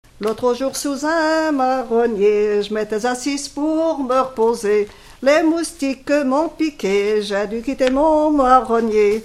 Localisation Brouzils (Les)
Genre brève
Catégorie Pièce musicale inédite